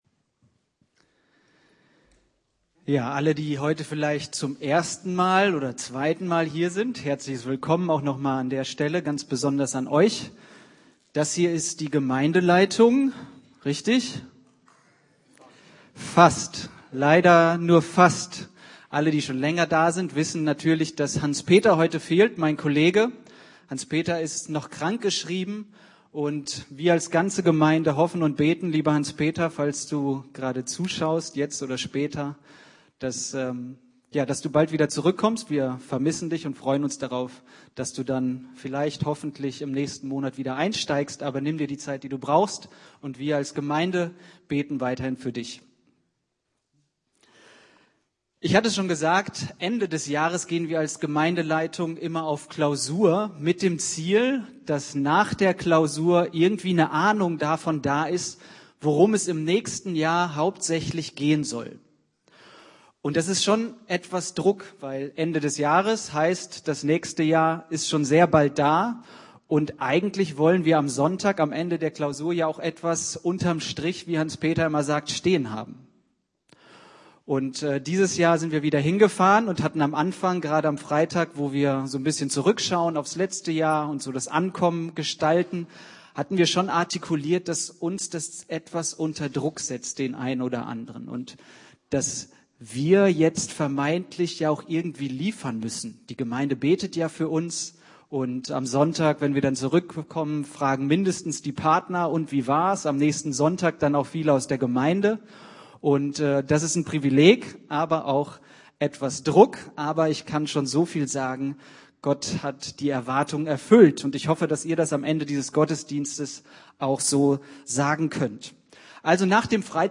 Januar 2026 Predigt Sein Plan ist größer https